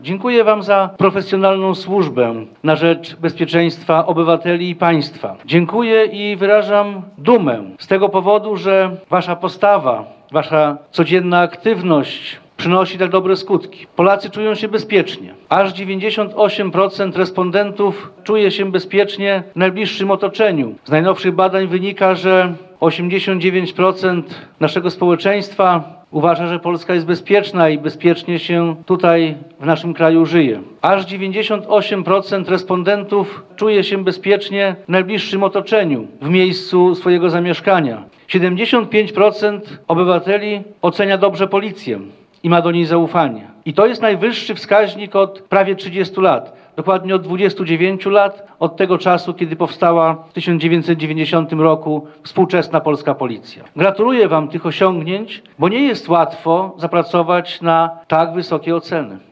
Później zebrani przeszli na plac Marszałka Józefa Piłsudskiego, gdzie odbyły się oficjalne wystąpienia, wręczenia odznaczeń państwowych, resortowych oraz nominacji na wyższe stopnie policyjne funkcjonariuszom komend z Suwałk, Sejn i Augustowa. Za służbę dziękował zebranym policjantom Jarosław Zieliński, odpowiedzialny za służby mundurowe, sekretarz stanu w Ministerstwie Spraw Wewnętrznych i Administracji.